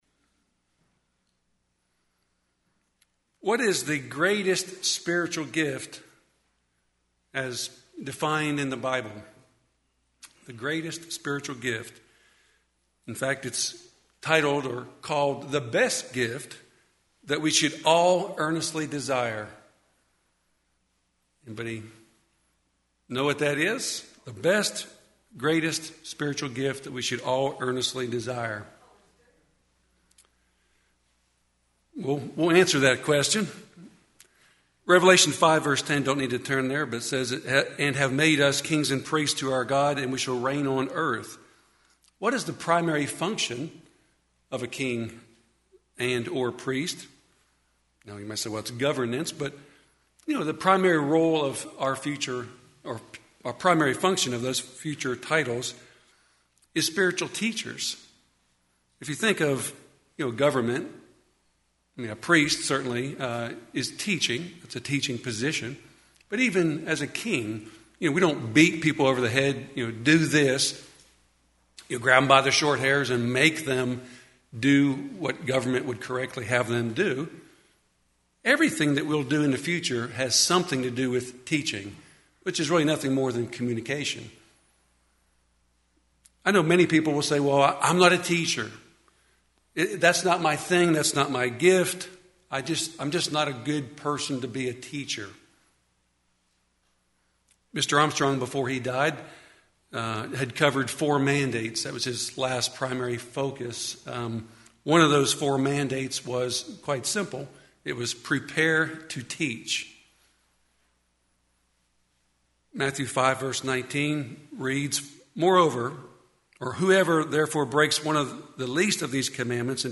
Given in Nashville, TN
sermon